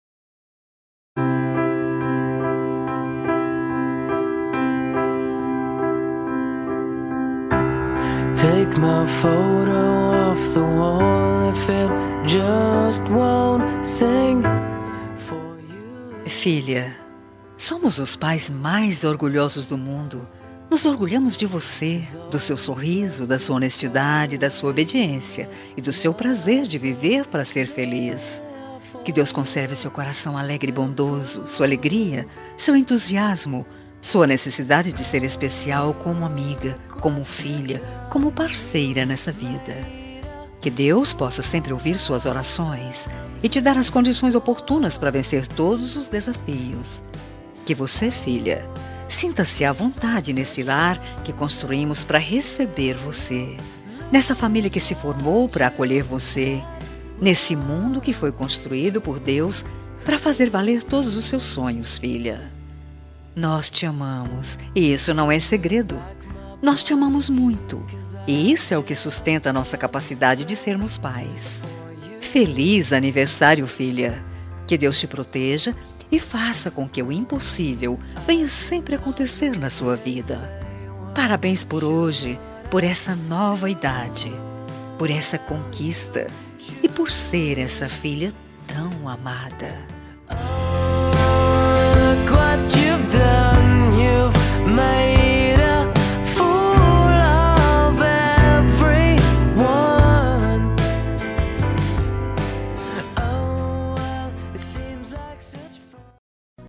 Aniversário de Filha – Voz Feminino – Cód: 5219 – Plural
5219-aniver-filha-fem-plural.m4a